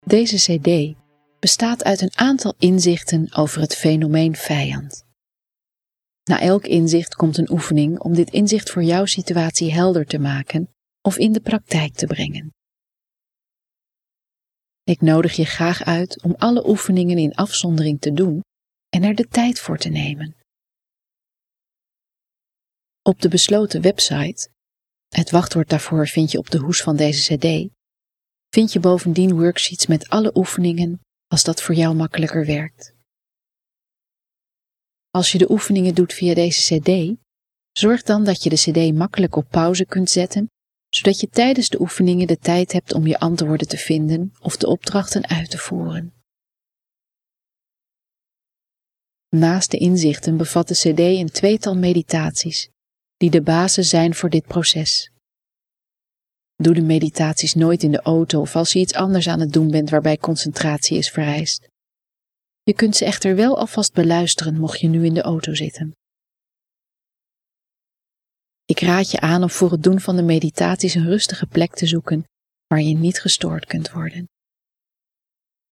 Het luisterboek bestaat uit twee delen: